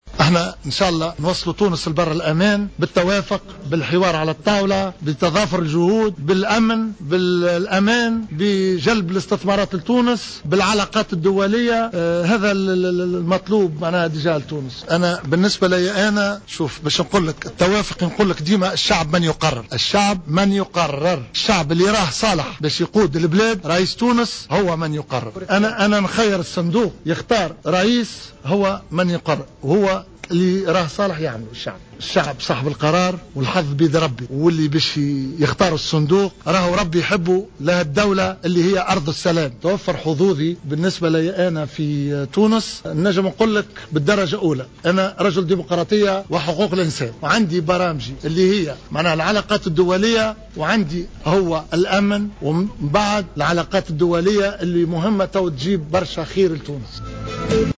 وأضاف خلال ندوة صحفية أن الاحتكام إلى الصندوق هو الفيصل.